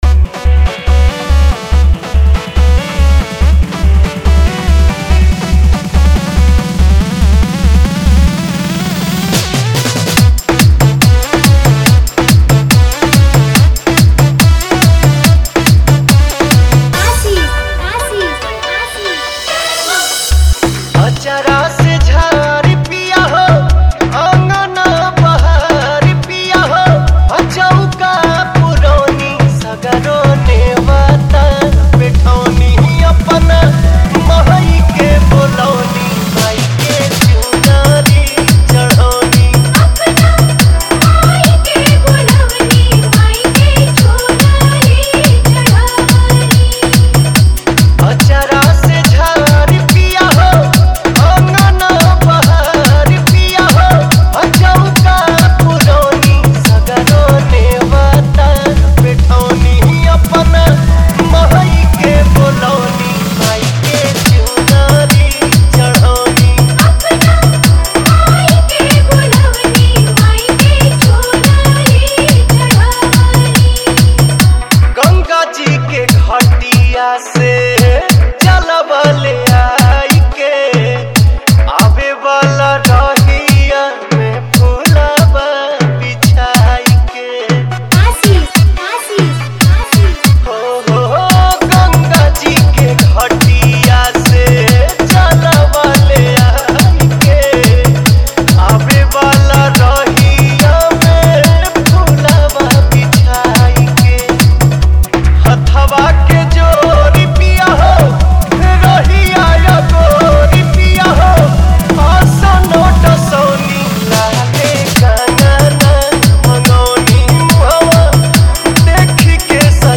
All Bhakti Dj Remix Songs